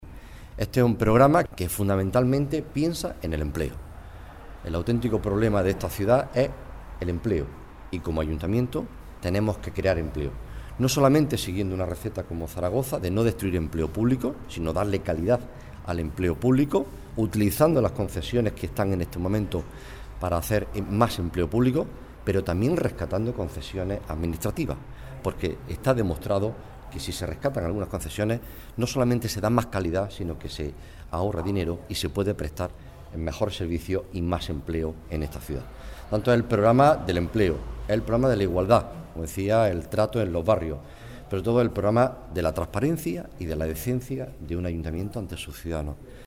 Juan Carlos Pérez Navas: Conferencia Política Almería